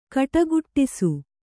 ♪ kaṭaguṭṭisu